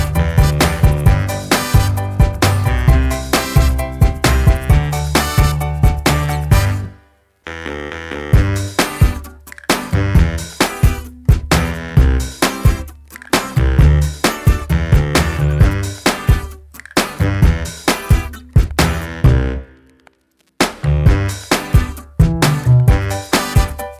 Jazz / Swing